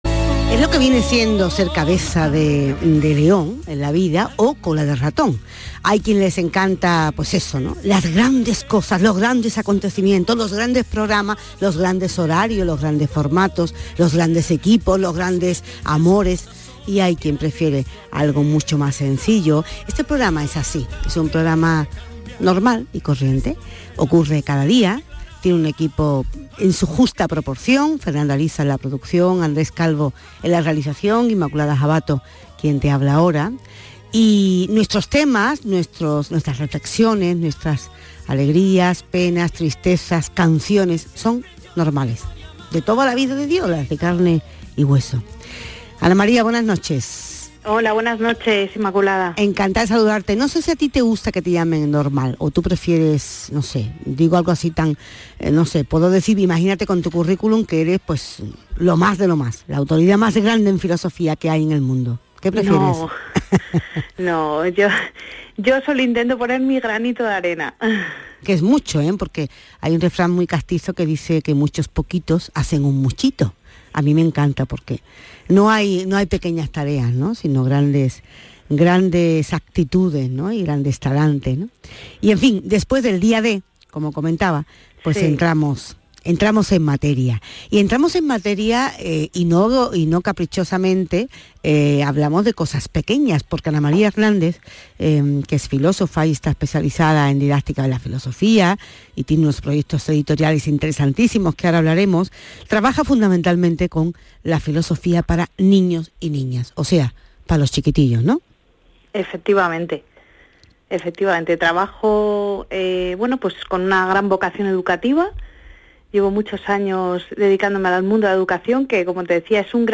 entrevista-canal-sur-radio1.mp3